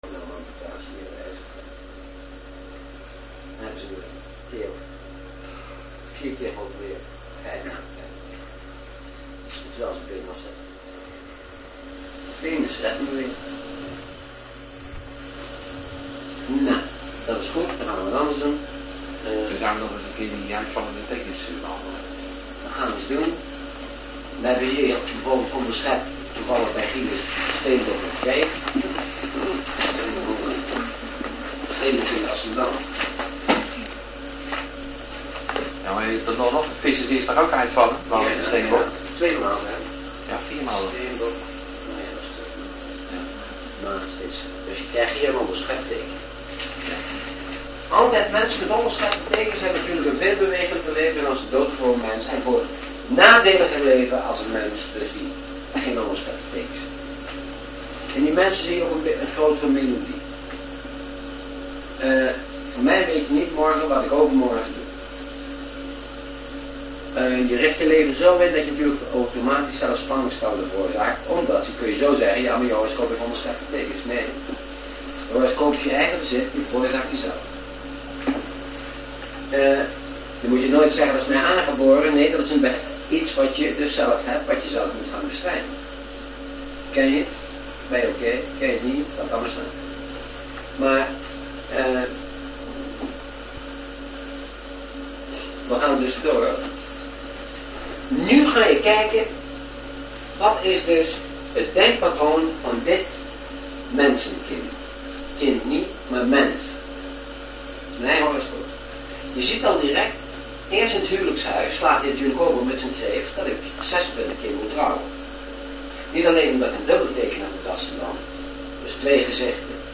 HIER het 6 minuten durende geluidsfragment van een les uit 1978.